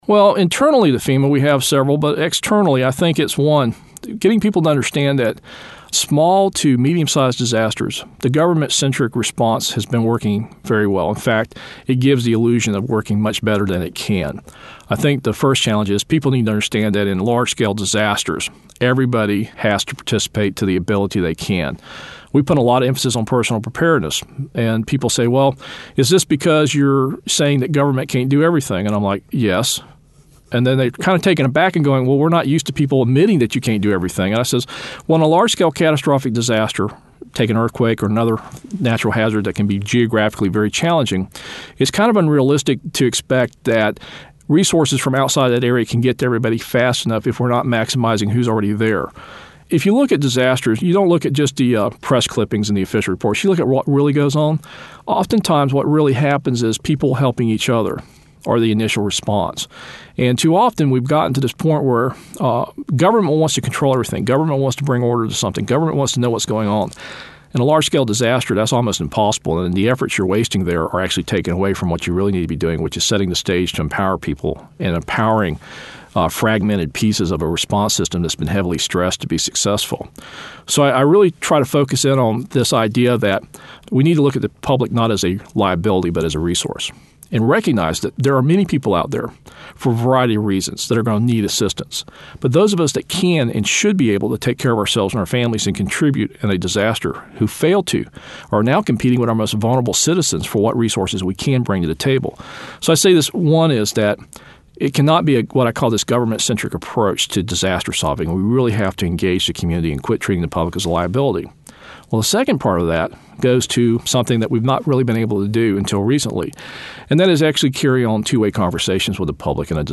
A Conversation with W. Craig Fugate, FEMA Administrator